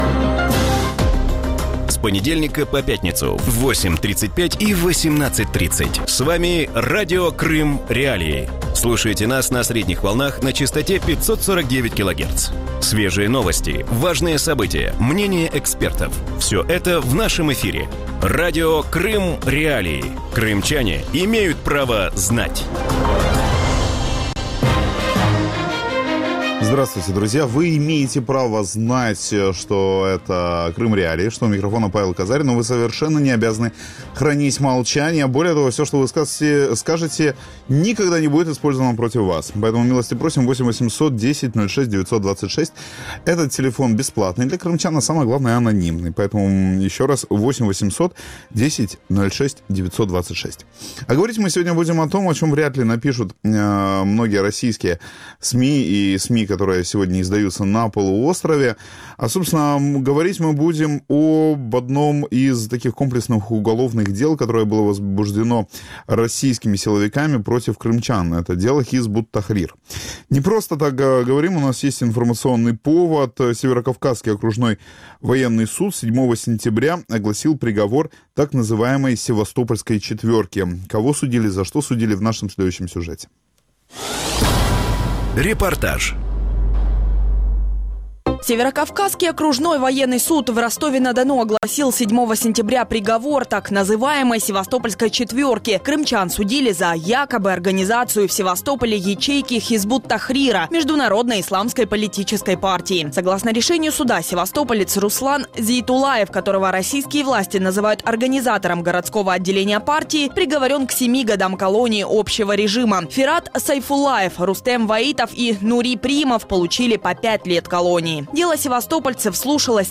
У вечірньому ефірі Радіо Крим.Реалії обговорюють вирок кримчанам у справі Хізб ут-Тахрір. Чи можна говорити про політичне підґрунтя справи, чим займаються члени організації на півострові і чому російська влада їх переслідує?